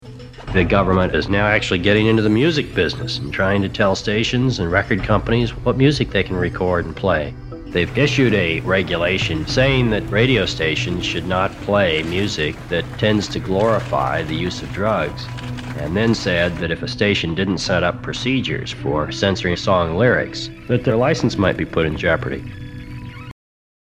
Apple TV “1971” Documentary, Premiere Date 21 May 2021. In episode two, a 1970s recording of Nicholas Johnson states, “The government is now actually getting into the music business and trying to tell stations and record companies what music they can record and play.